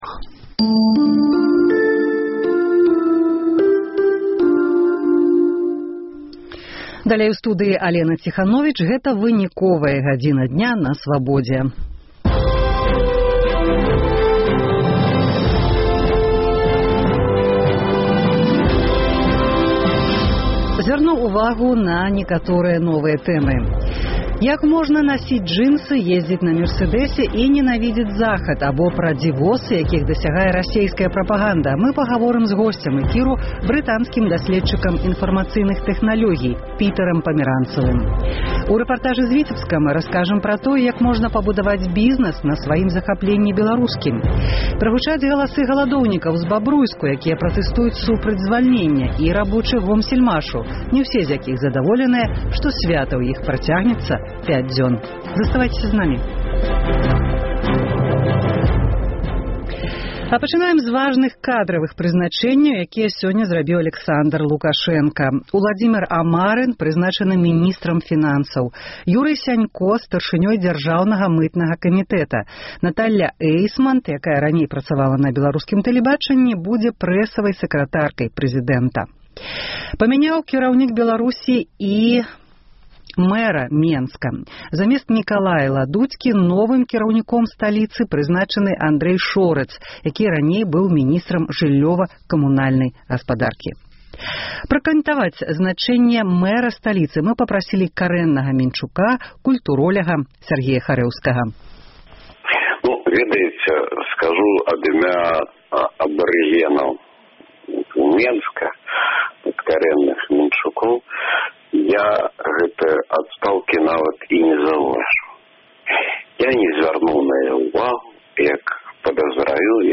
Кажа госьць эфіру – Пітэр Пямяранцаў аб прынцыпах расейскай прапаганды. Зьмена менскага мэра і іншыя прызначэньні – у бліц-аналізе Свабоды. Куды можа прывесьці палітычны крызыс у Грузіі.